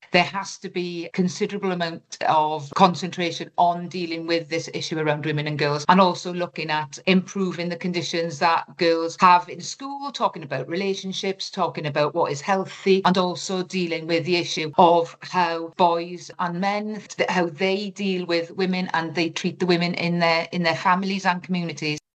Tonia Antoniazzi is Chair of the Northern Ireland Affairs Committee……………